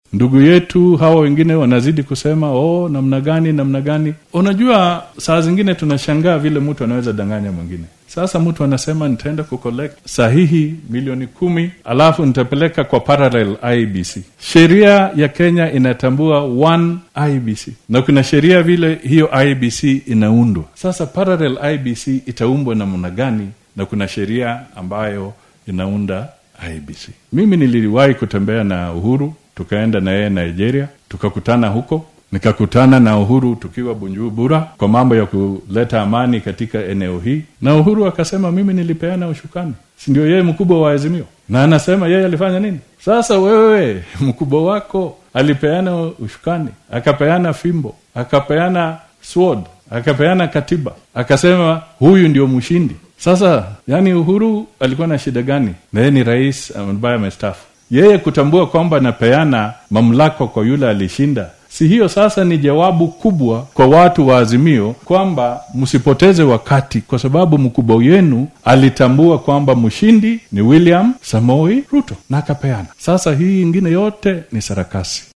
Musalia Mudavadi ayaa xilli uu ku sugnaa tuulada Iguyio ee deegaan baarlamaneedka Ikolomani ee ismaamulka Kakamega, ku celceliyay in dowladda dhexe aynan marnaba fiirsan doonin in la sameeyo mudaaharaadyo wax u dhimaya xasiloonida guud.